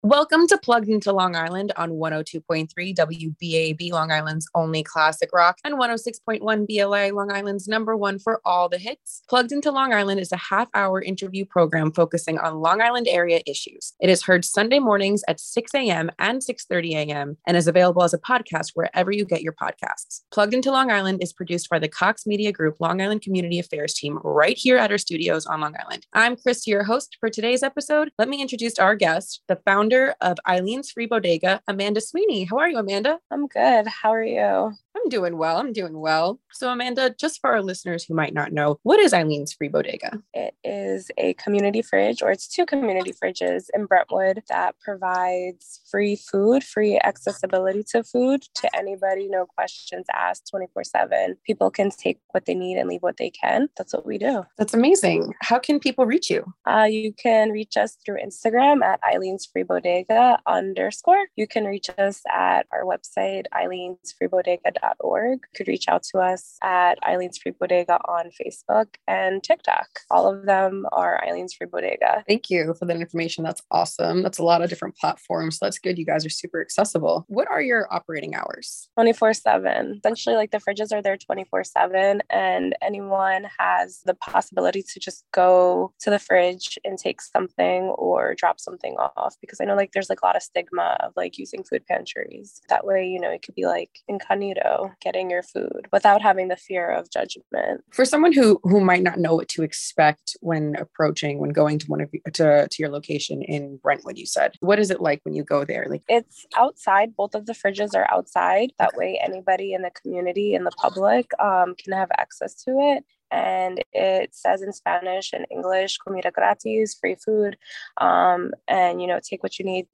Interview with BLI 106.1 - Dec 16th, 2022